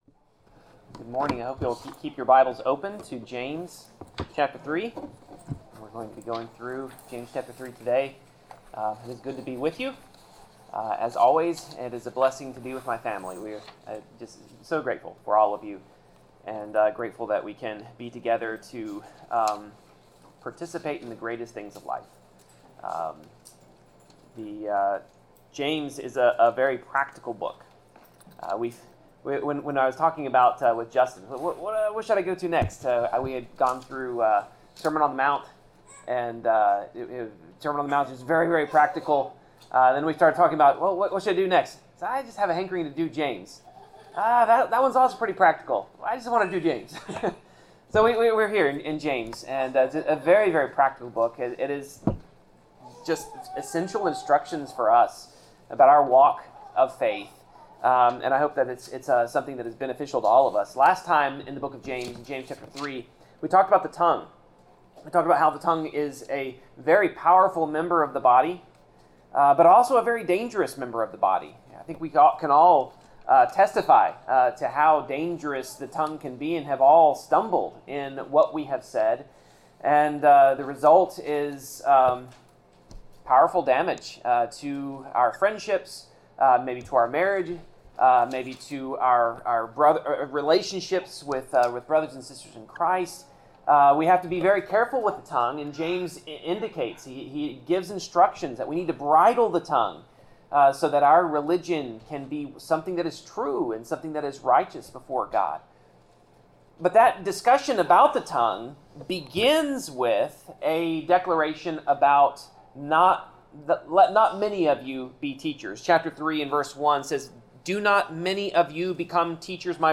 Passage: James 3:13-4:12 Service Type: Sermon